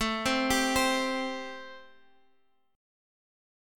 C5/A chord